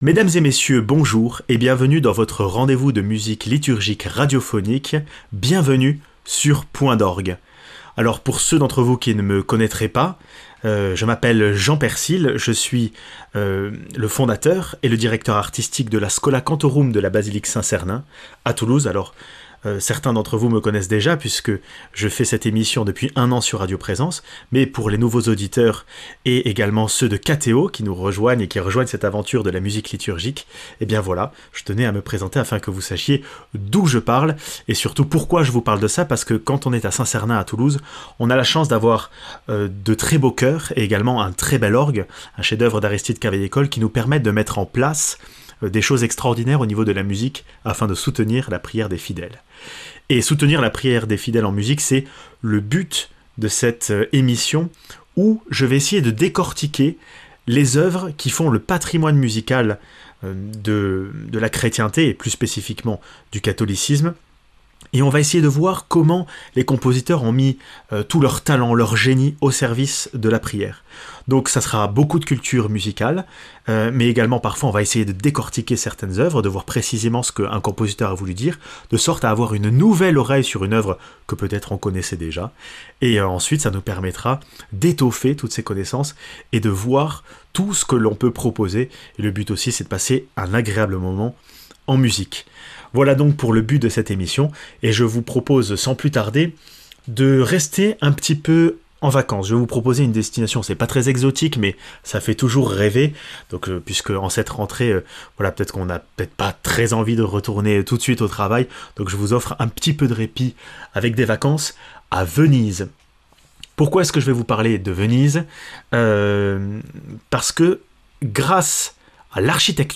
Certains compositeurs ont voulu donner aux auditeurs et à l’assemblée une atmosphère particulière aux célébrations en spatialisant les musiciens.